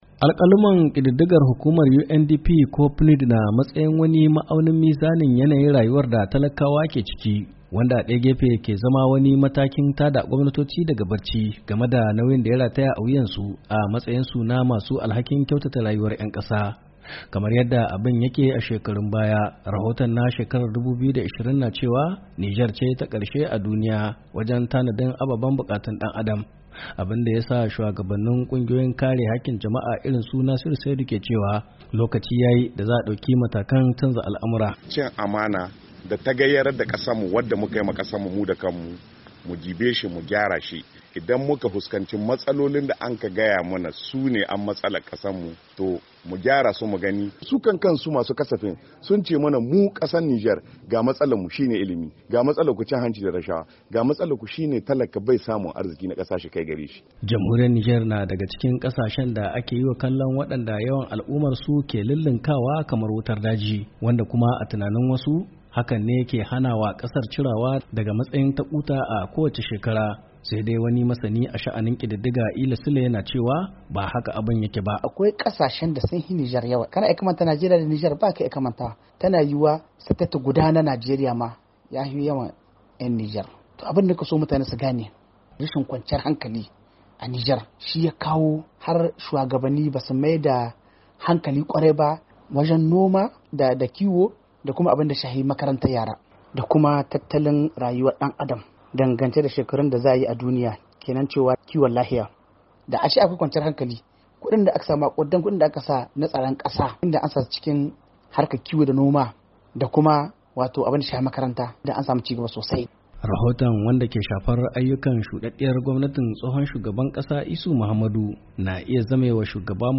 Nijar Na Baya Wajen Samar Da Ababen More Rayuwa A Kasar - Rahoto